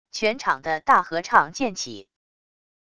全场的大合唱渐起wav音频